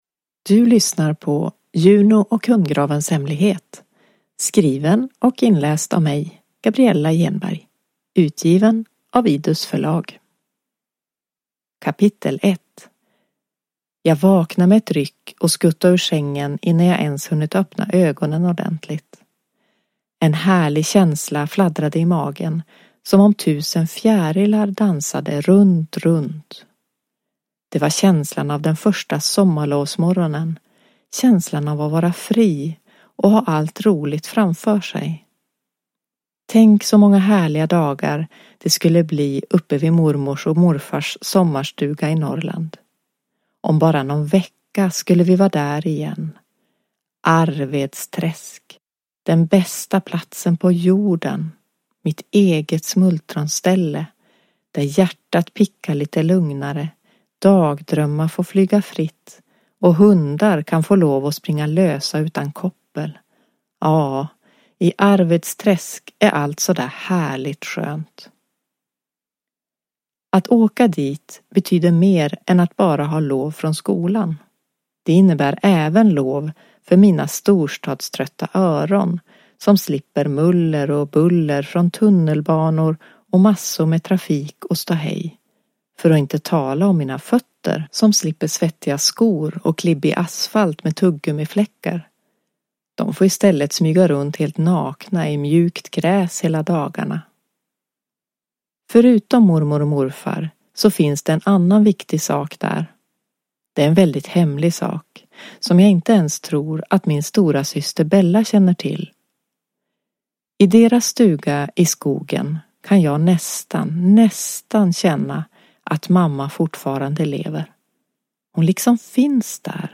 Juno och hundgravens hemlighet – Ljudbok